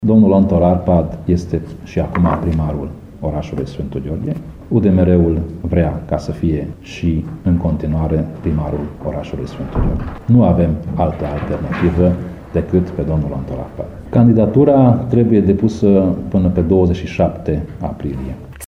Preşedintele UDMR Covasna, Tamas Sandor: